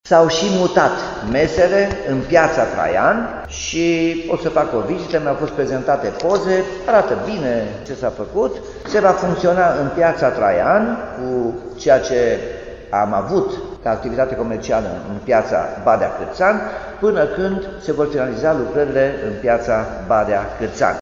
“S-au și mutat mesele în Piața Traian. O să fac o vizită, mi-au fost prezentate poze, arată bine ceea ce s-a făcut. Se va funcționa în Piața Traian cu ceea ce am avut activitate comercială în Piața Badea Cârțan, până când se vor finaliza lucrările”, a declarat Nicolae Robu.